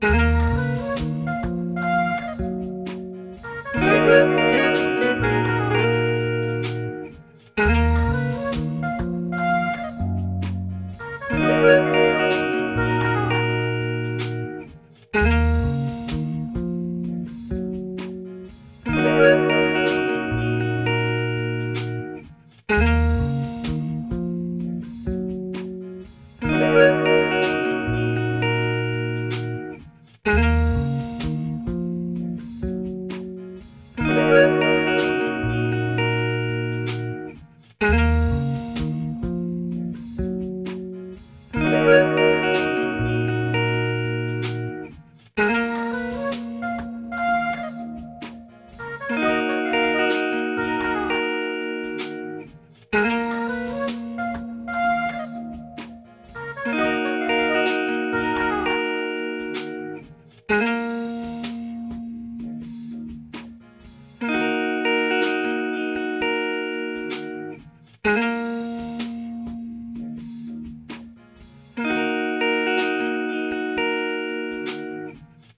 guitar thing 127.wav